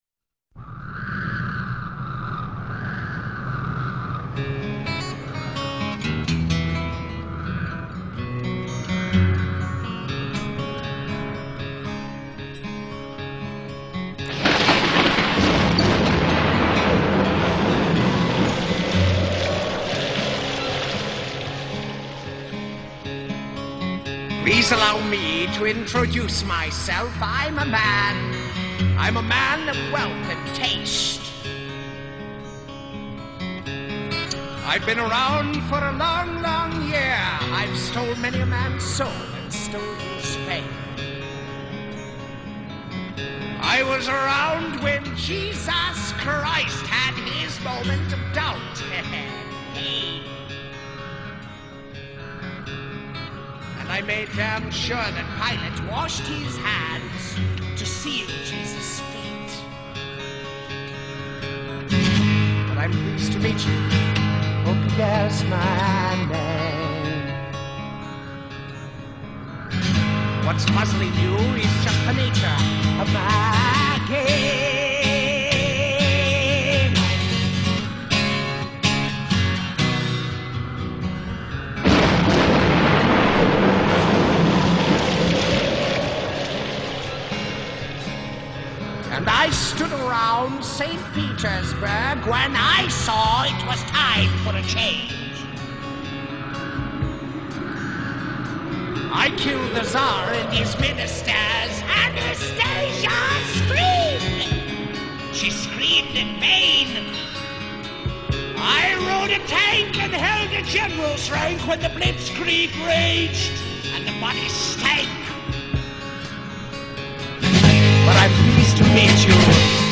over three hours of shock rock!